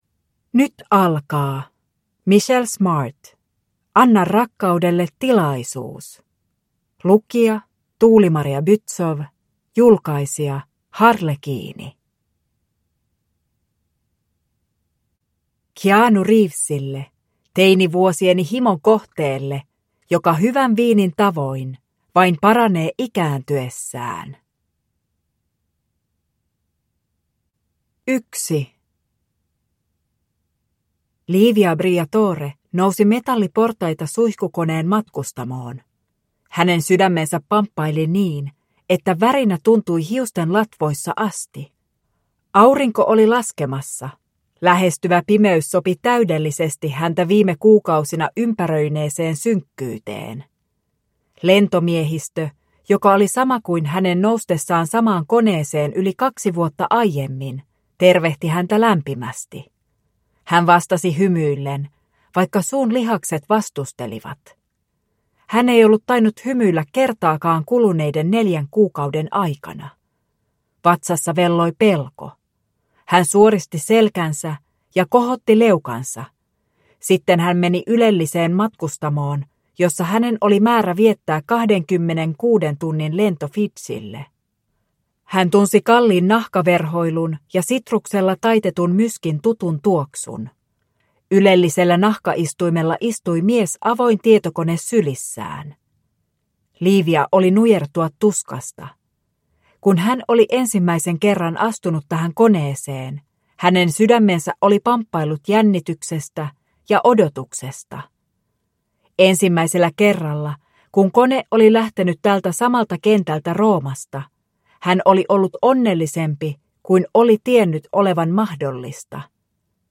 Anna rakkaudelle tilaisuus (ljudbok) av Michelle Smart